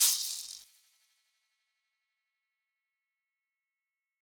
MD (Shaker) (2).wav